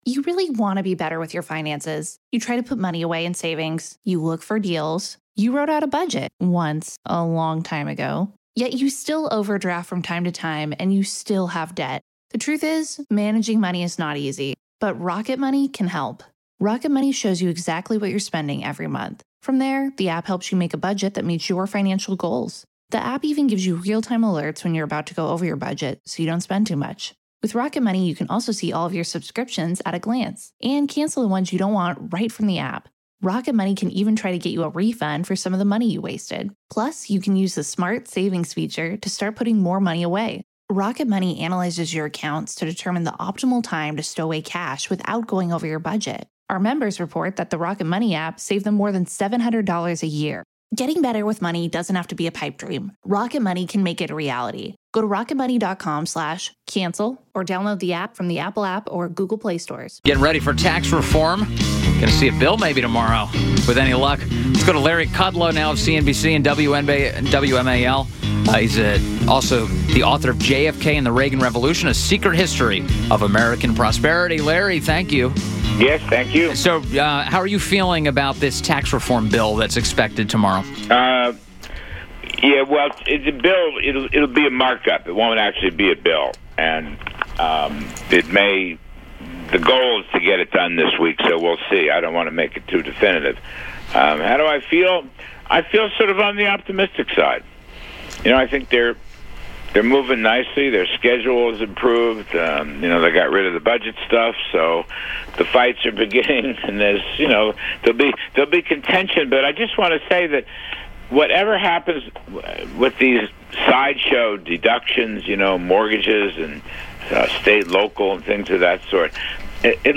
WMAL Interview - LARRY KUDLOW - 10.31.17